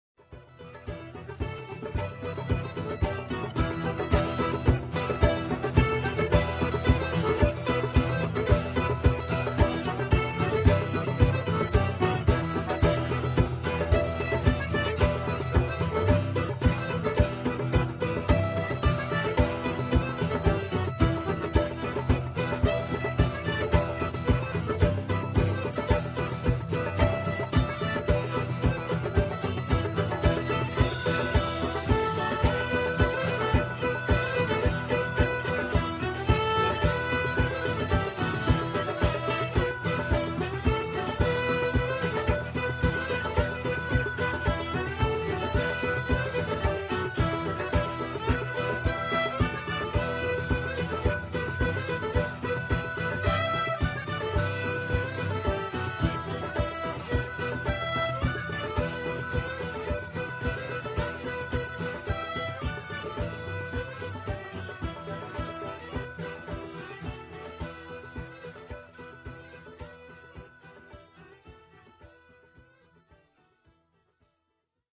"Global beat ceilidh" - The Times Metro